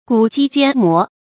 轂擊肩摩 注音： ㄍㄨˇ ㄐㄧ ㄐㄧㄢ ㄇㄛˊ 讀音讀法： 意思解釋： 肩膀和肩膀相摩，車輪和車輪相撞。